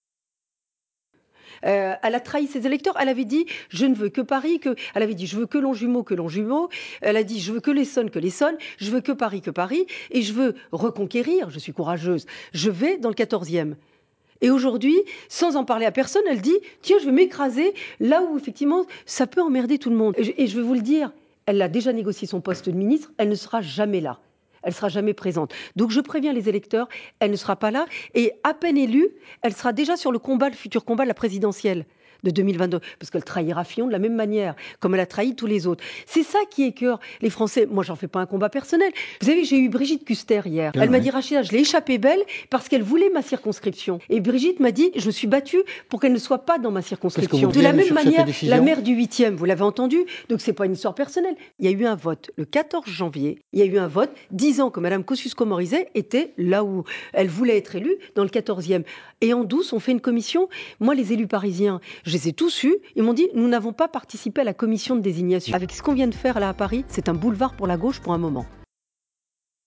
Extraits de l'interview de Rachida Dati sur France Info le 19 janvier 2017 : Législatives 2017 - Rachida Dati ne veut pas de Nathalie Kosciusko-Morizet sur ses terres.mp3 (1.07 Mo)